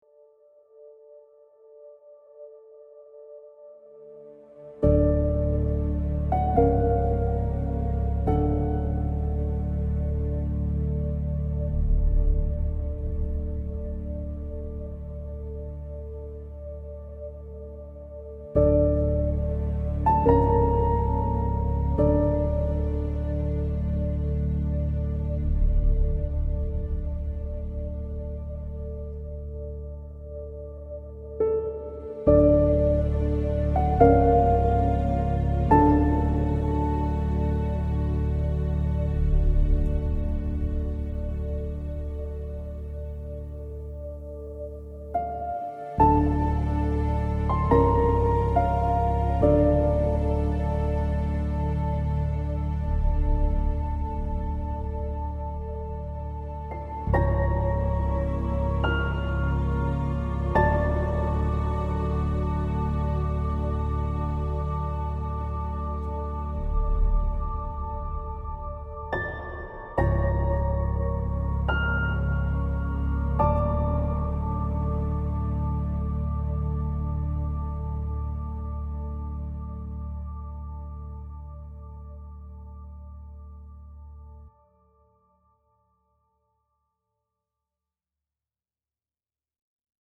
Piano-Bass-Pad Only